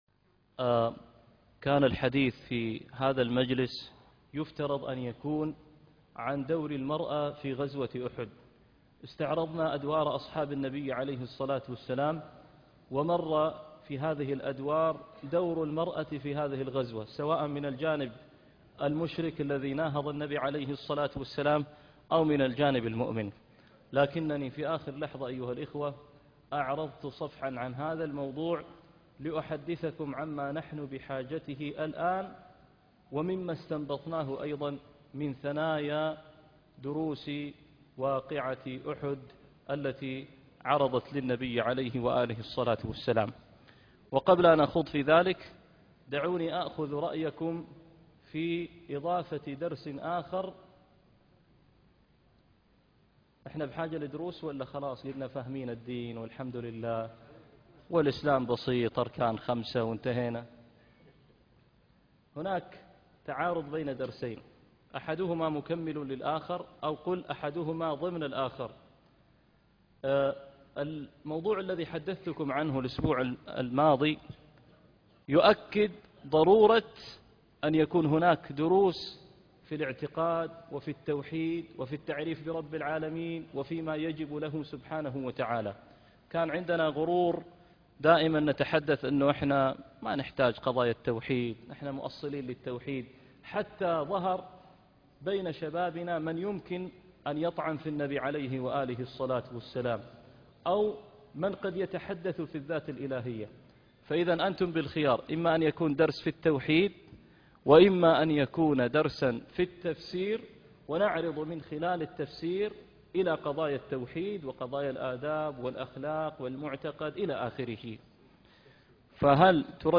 درس السيرة النبوية